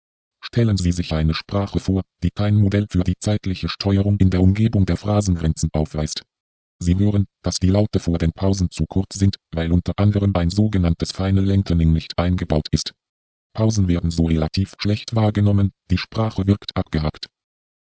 Sie hören, dass die Laute vor den Pausen zu kurz sind, weil unter anderem ein sogenanntes 'final lengthening' nicht eingebaut ist. Pausen werden so relativ schlecht wahrgenommen, die Sprache wirkt abgehackt (